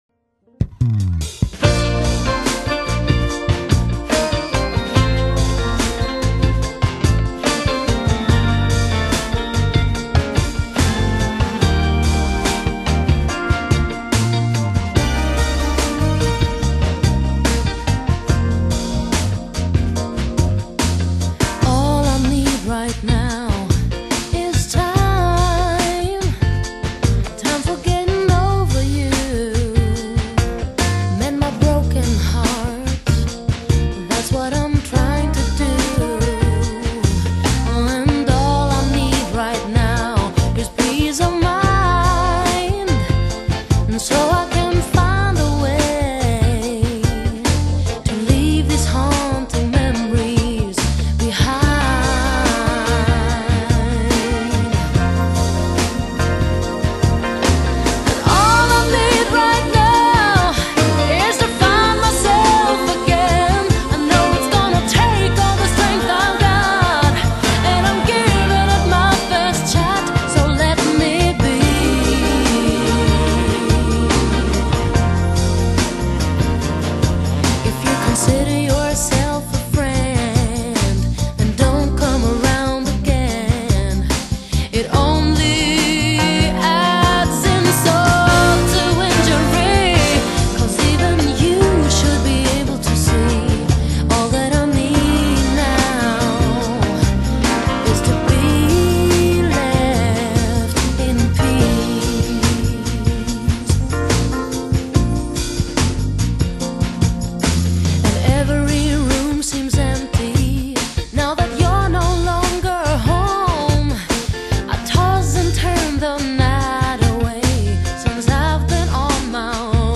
【瑞典女歌手】